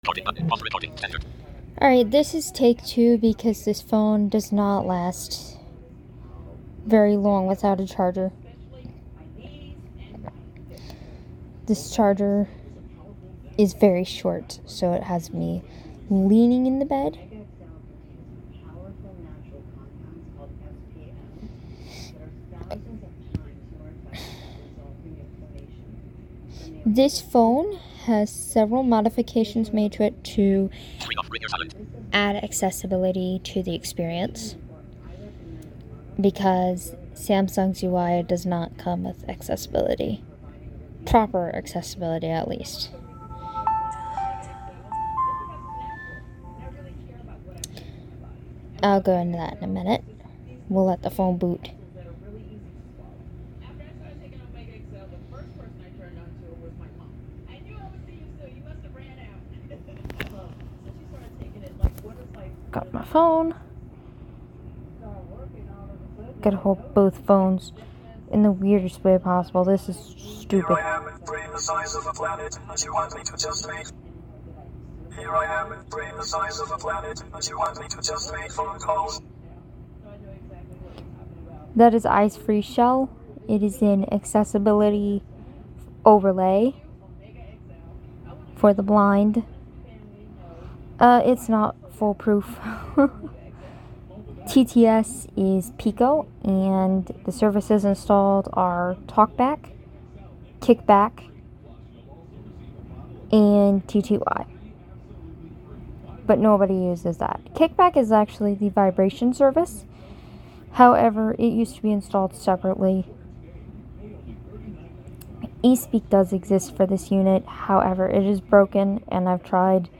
Anyways, i hope this brings you back to the early days of Android, where Accessibility was not fully featured, and was just a nightmare of buttons. Eyes Free Shell will be featured, however, it is no longer being developed. It was an accessible Android launcher, made to give some level of accessibility to blind android adopters. This phone runs Android 2.2, if you are curious!